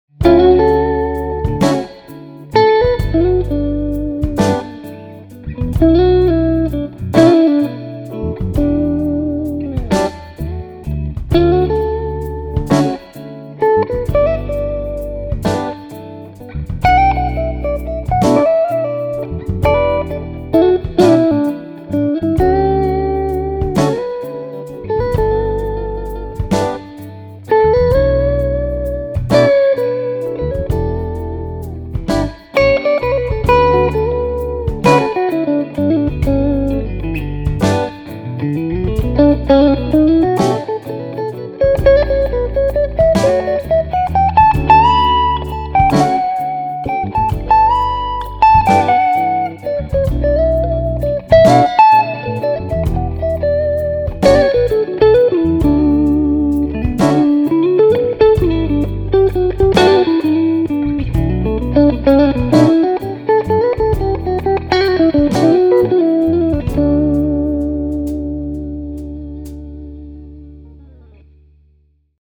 The bass is tight for a matter of speaking, not flubby.
The treble frequencies are clear with just enough brightness but not too dark when they compress.
63-2nd-Position-Clean.mp3